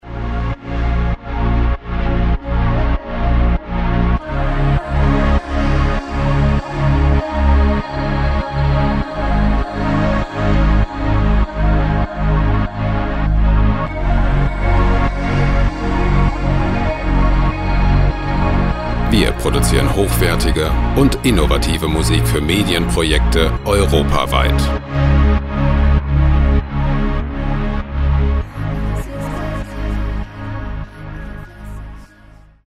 Ambient Loops gemafrei
Musikstil: Cinematic Ambient
Tempo: 99 bpm